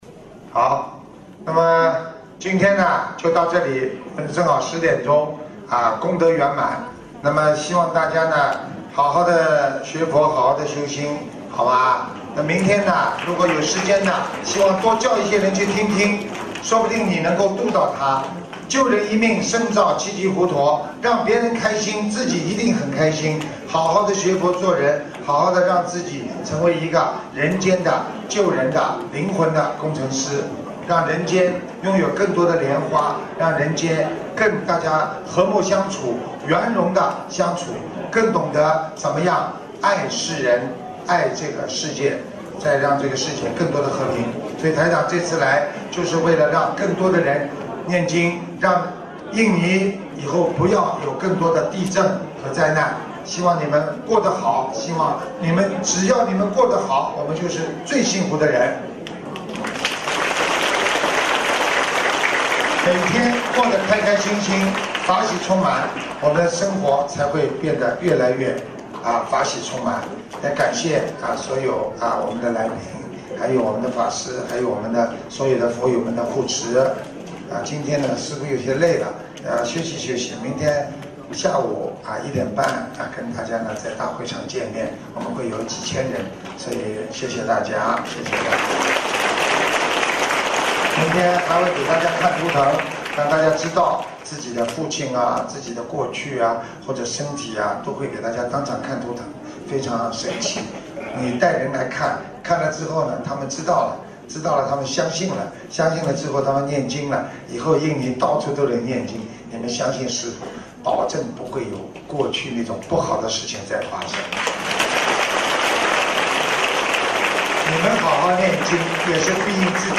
【2016印度尼西亚·巴淡岛】2月17日 佛友见面会 文字 - 2016法会合集 (全) 慈悲妙音